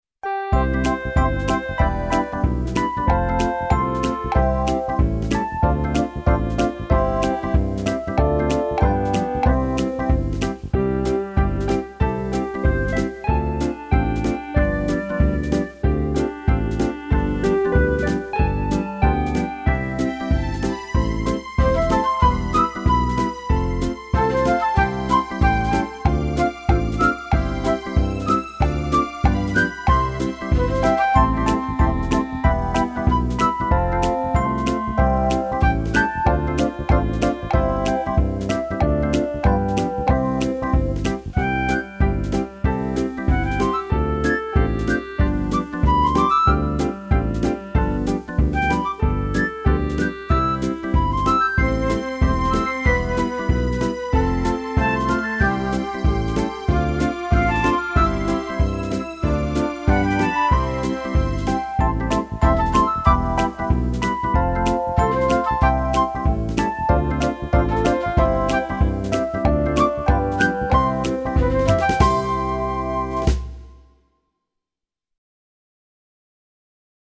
Скачать минус: